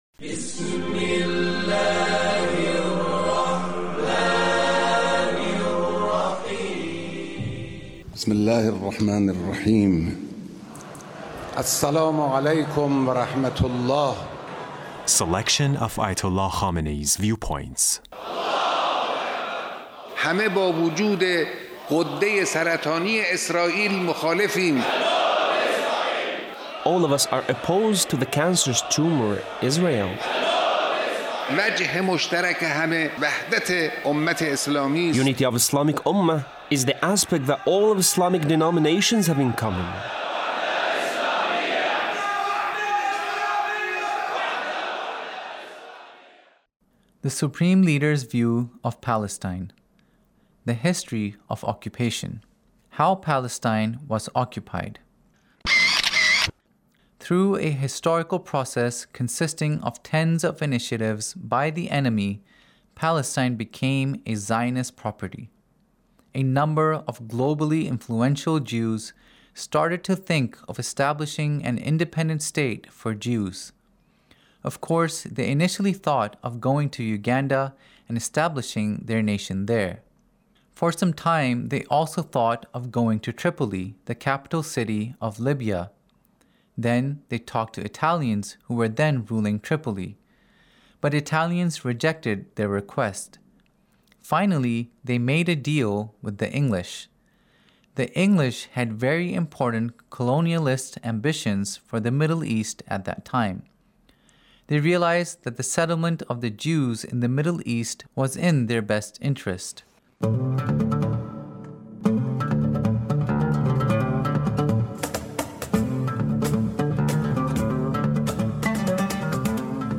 Leader's Speech in a Meeting with the Three Branches of Government Repentance